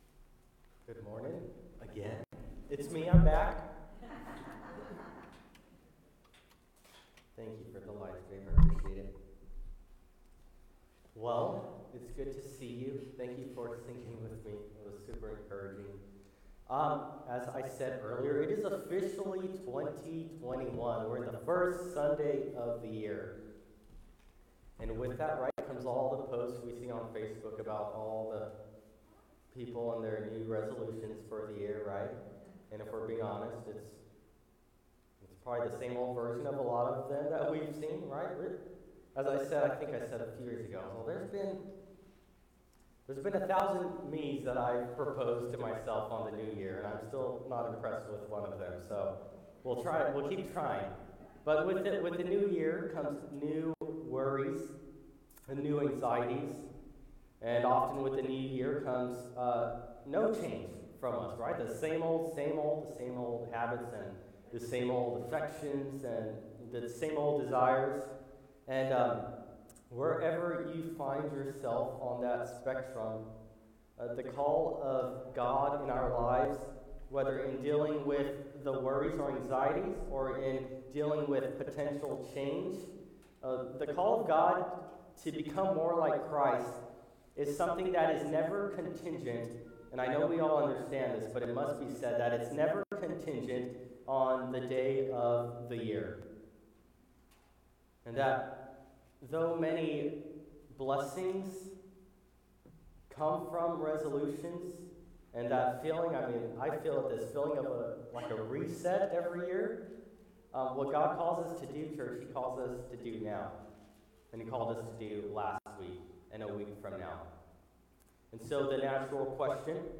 This sermon, part of our series “Journey to the Cross,” reflects on Jesus’ path to crucifixion in Luke.